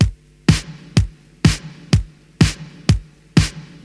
• 125 Bpm Drum Groove G Key.wav
Free breakbeat - kick tuned to the G note. Loudest frequency: 1231Hz
125-bpm-drum-groove-g-key-GR6.wav